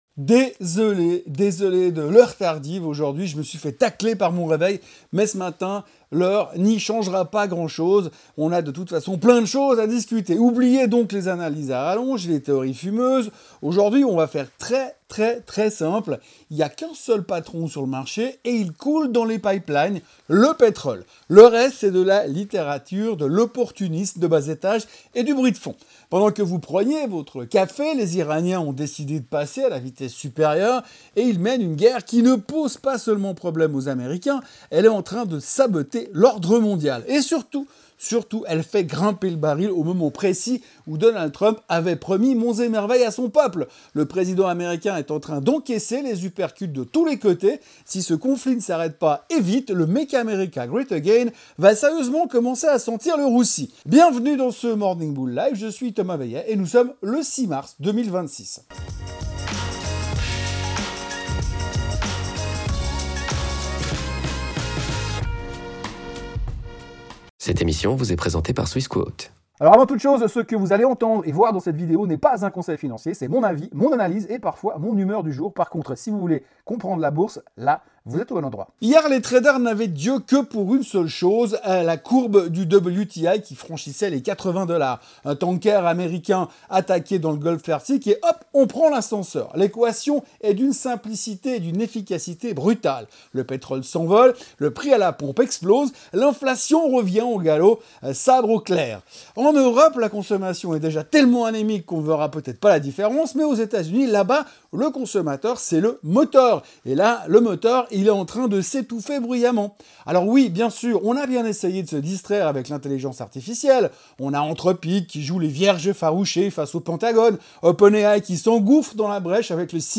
Pour des raisons techniques, ce matin l’audio est celui du Morningbull Live sur YouTube…
morningbull-live-du-6-mars-2026.mp3